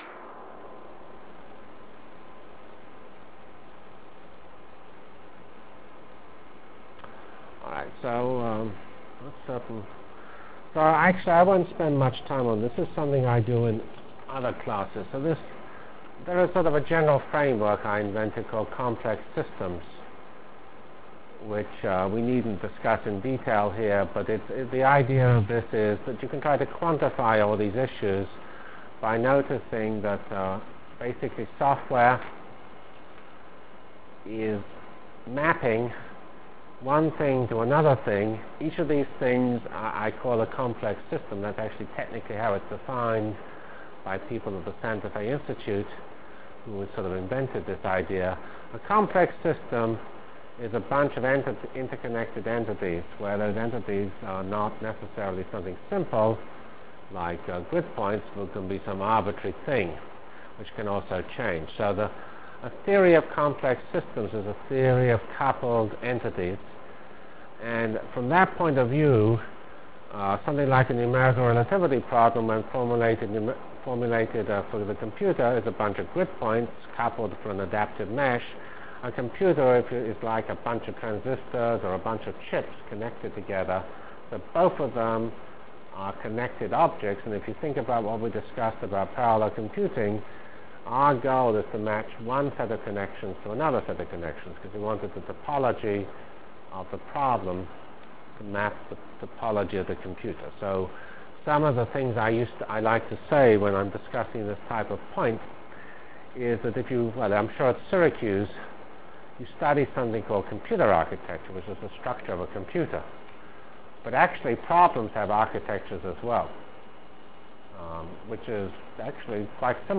Delivered Lectures of CPS615 Basic Simulation Track for Computational Science -- 24 September 96.